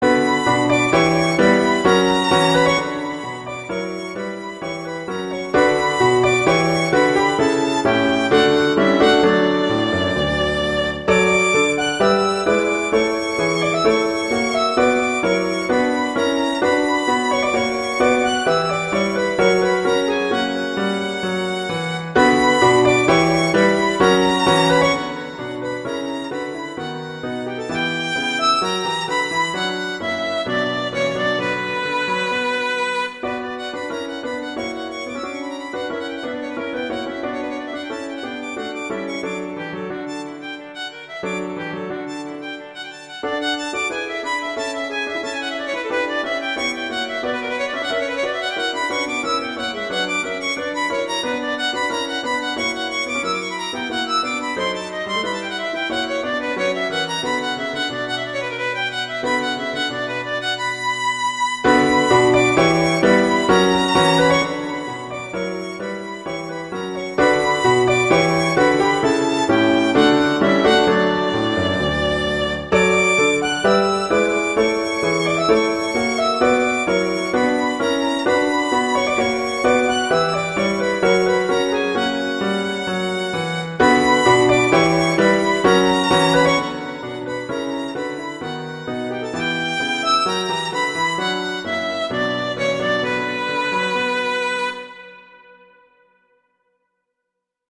transcription for violin and piano
violin and piano
classical